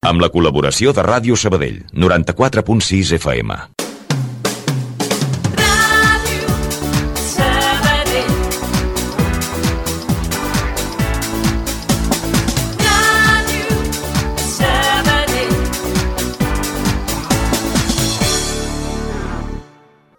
Identificació i indicatiu de l'emissora